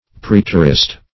\Pr[ae]t"er*ist\
praeterist.mp3